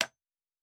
Nail 2_3.wav